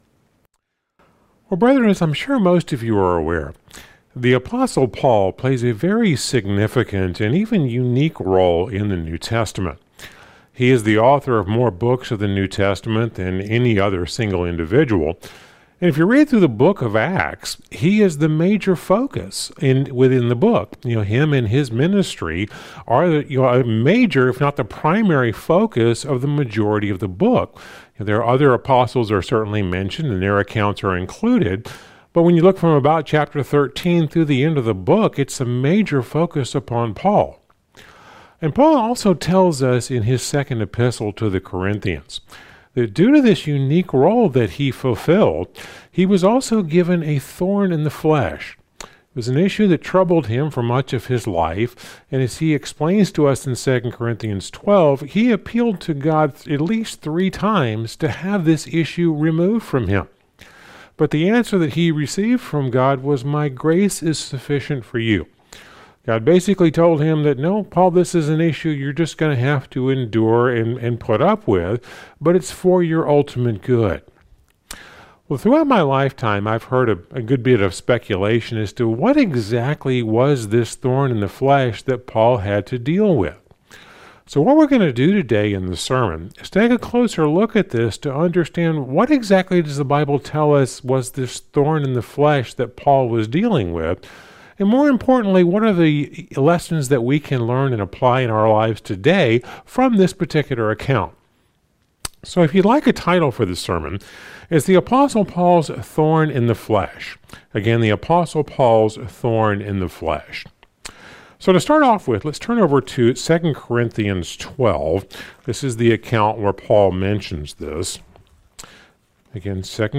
Sermons – Searching The Scriptures